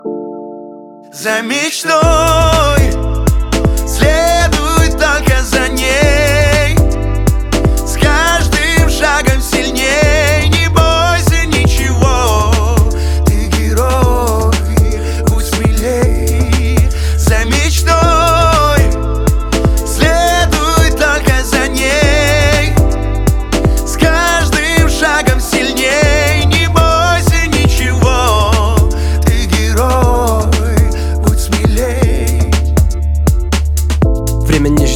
# TV Soundtrack